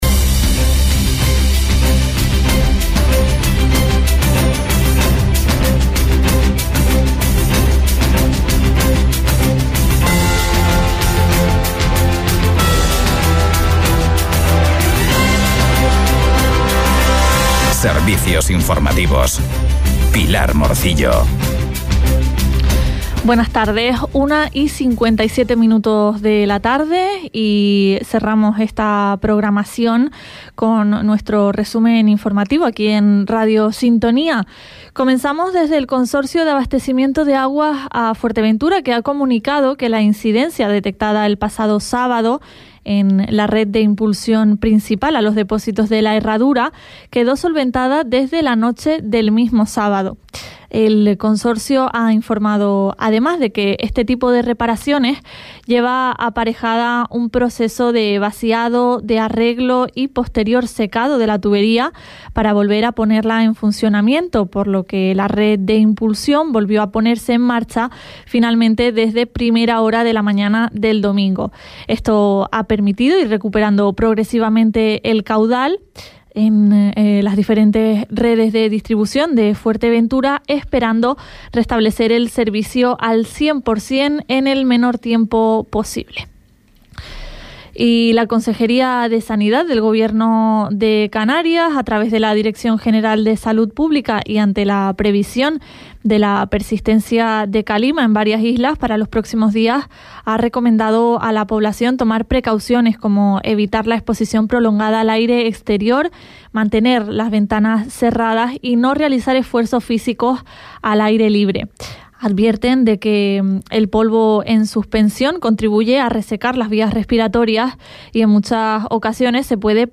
Informativos en Radio Sintonía - 09.10.23